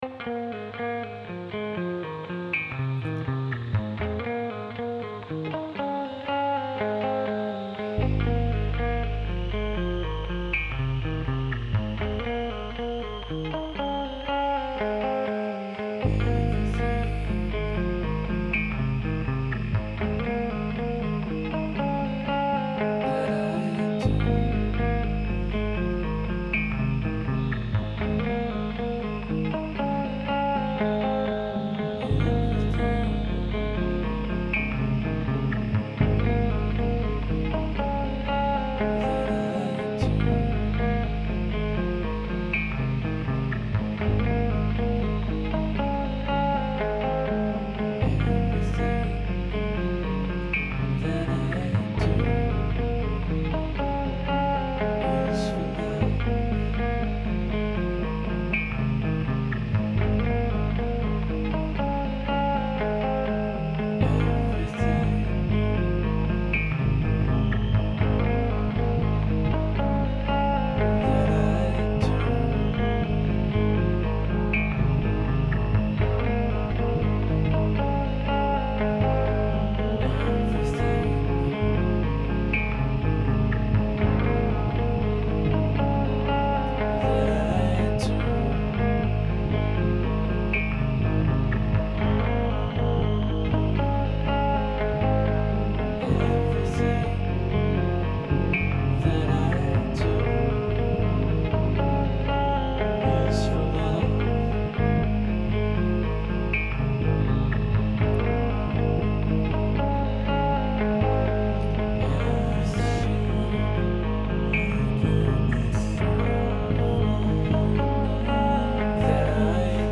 The shifting white noise in the distant background adds a lot.
Just a loop.
It's a tone poem, and those have a place on any given album. the wacky incidental background noises are kinda awesome tho.